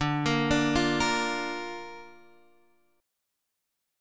Dm#5 Chord